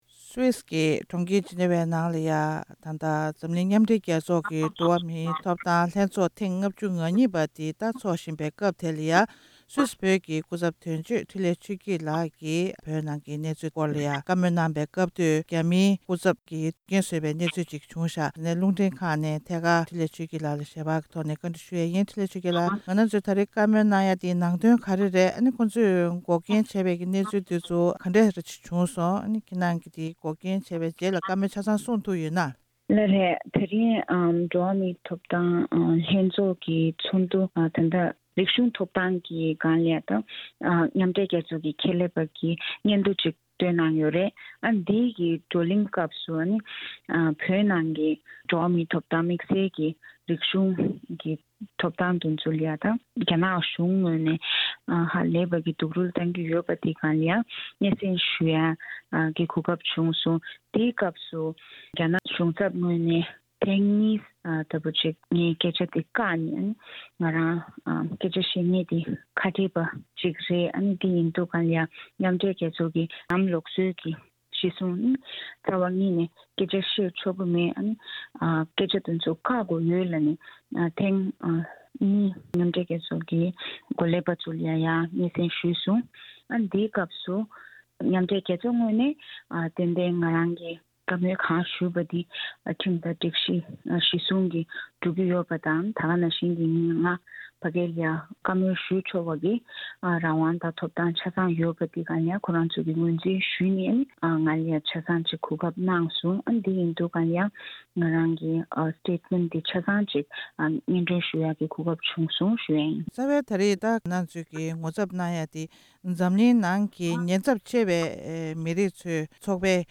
གནས་འདྲི་ཞུས་པ་ཞིག་གསན་གྱི་རེད།